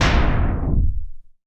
LC IMP SLAM 5C.WAV